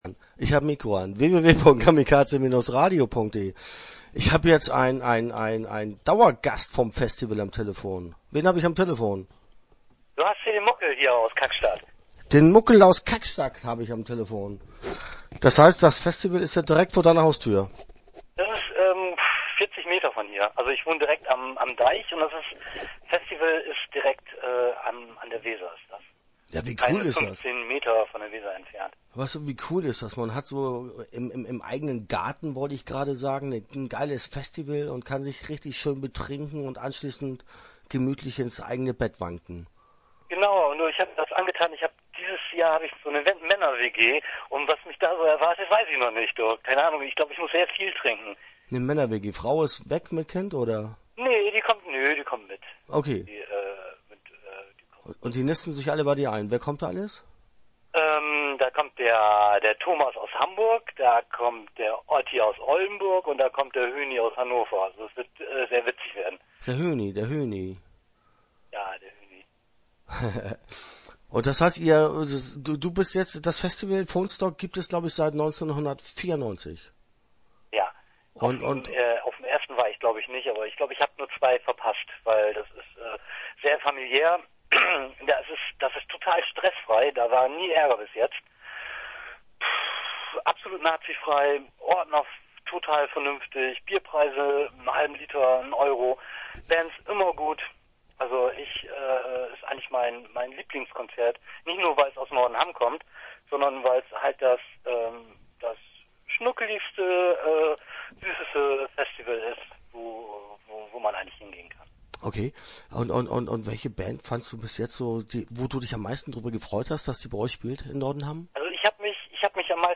Interview Teil 1 (15:22)